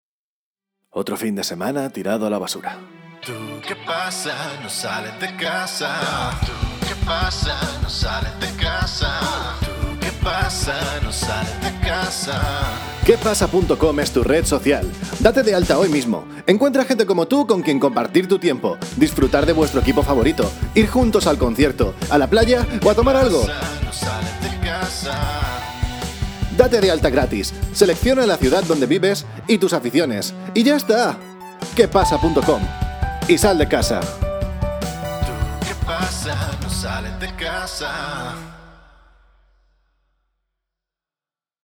kastilisch
Sprechprobe: Sonstiges (Muttersprache):
Spanish singer and voice artist from Spain.